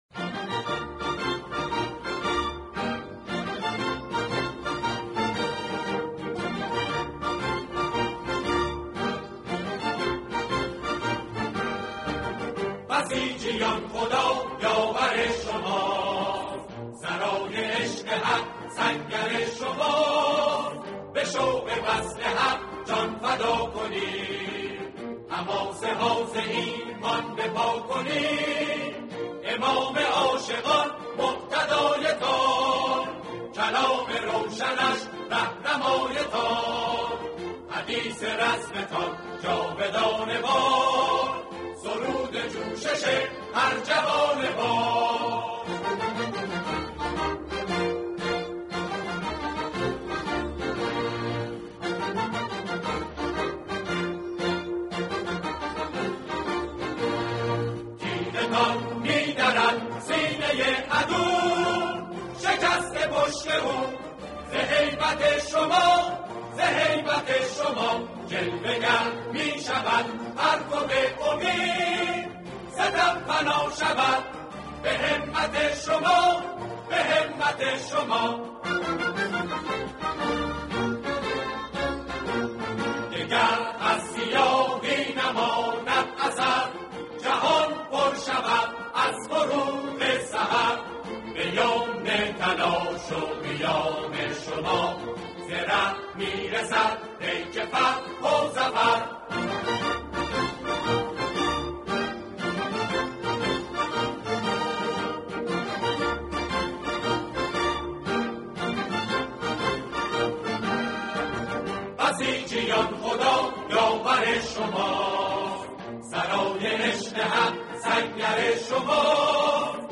اجرای گروهی
سرود